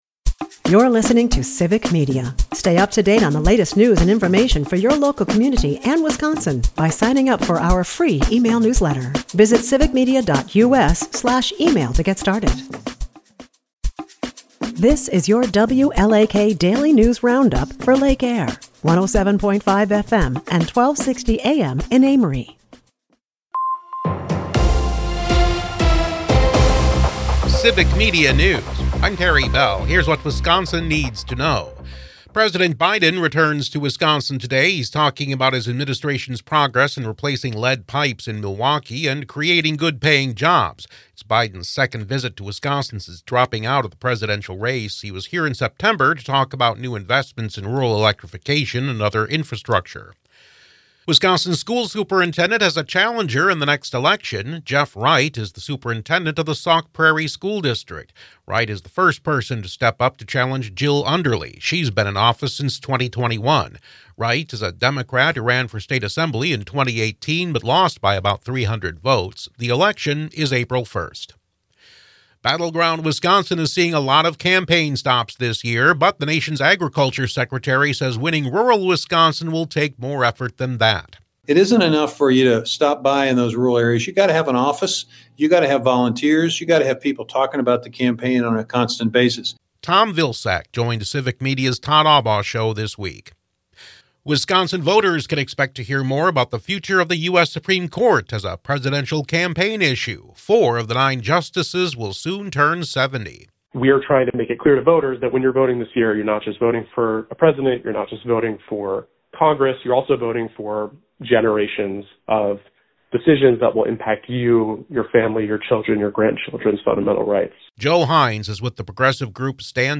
wlak news